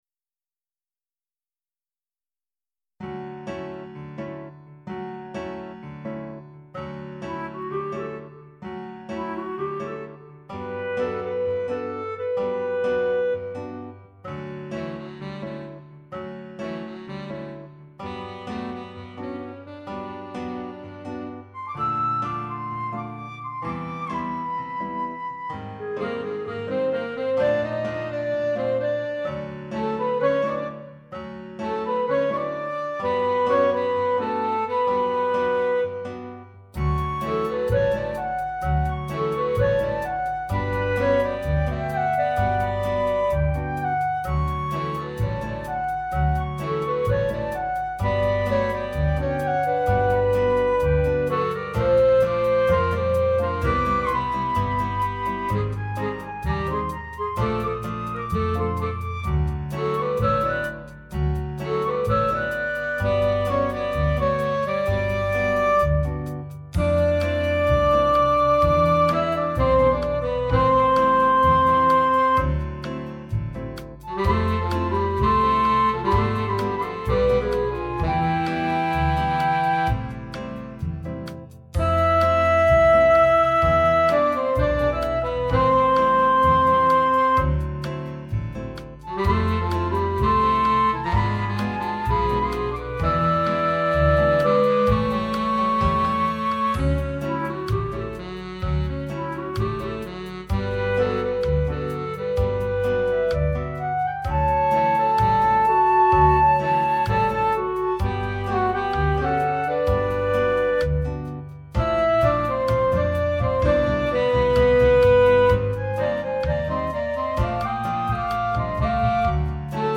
Instrumentation: C, Bb, Eb, Gtr, pno, bass, drums
An excellent classroom introduction to Latin American